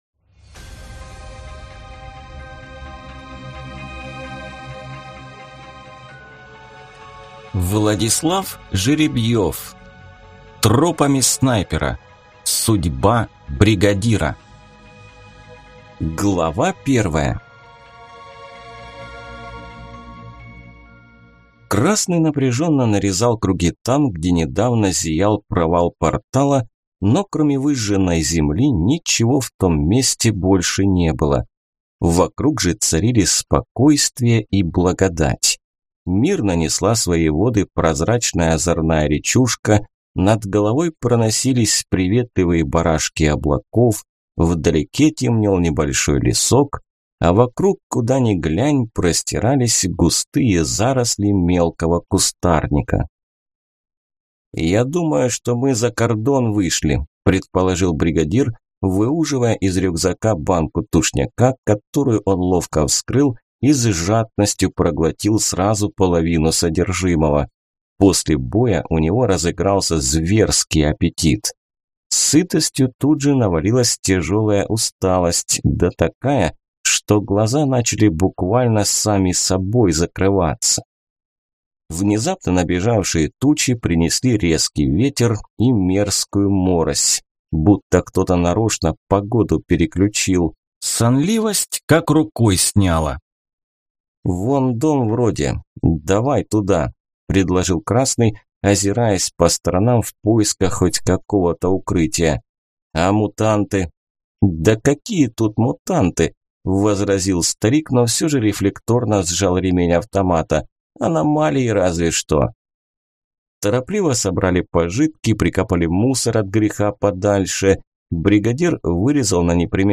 Аудиокнига Тропами Снайпера. Судьба Бригадира | Библиотека аудиокниг